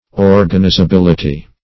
Meaning of organizability. organizability synonyms, pronunciation, spelling and more from Free Dictionary.
Search Result for " organizability" : The Collaborative International Dictionary of English v.0.48: Organizability \Or`gan*i`za*bil"i*ty\ ([^o]r`gan*[imac]`z[.a]*b[i^]l"[i^]*t[y^]), n. Quality of being organizable; capability of being organized.